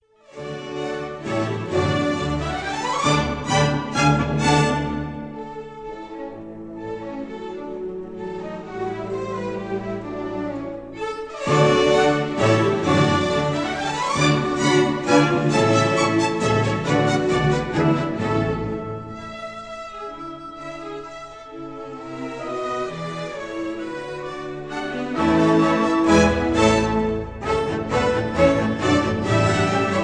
in D major